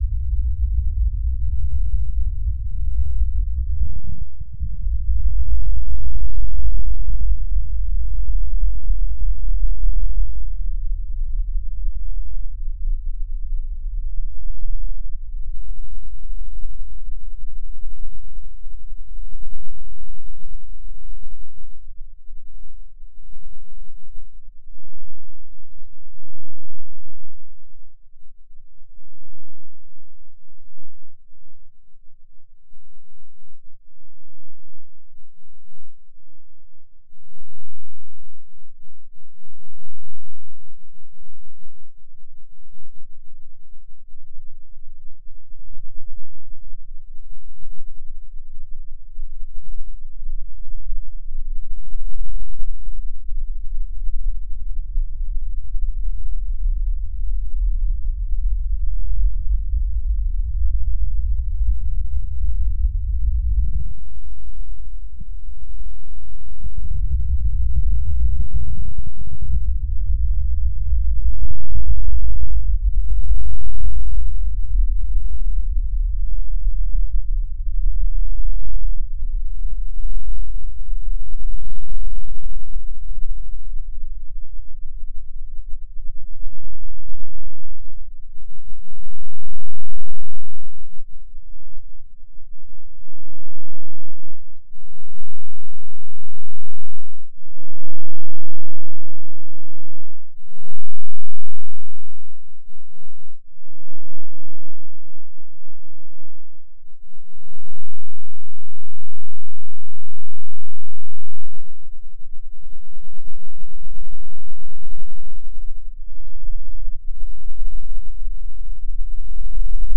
用absynth合成器制作的短小的无人机声音
描述： 我正在为我正在进行的项目制作的恐怖游戏或剪辑的环境轨道。
Tag: 环境 大气 雄蜂 恐怖 吓人 黑暗